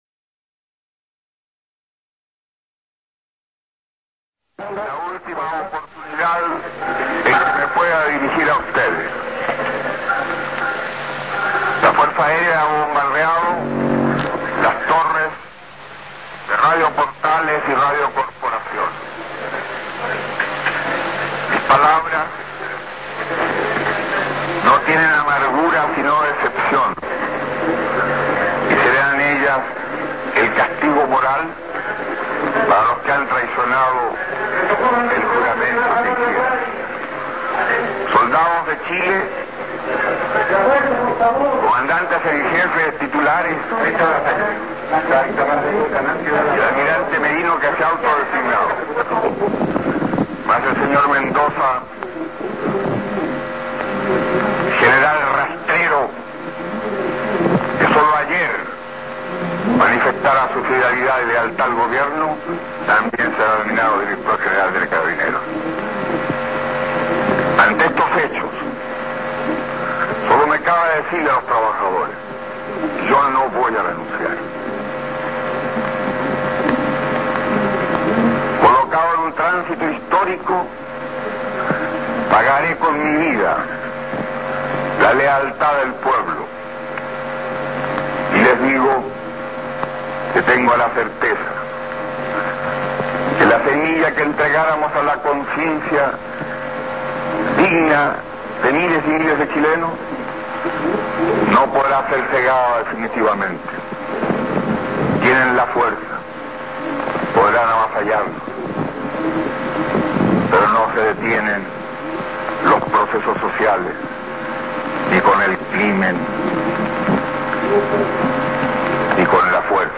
Salvador Allende, palacio de La Moneda, 11 de septiembre de 1973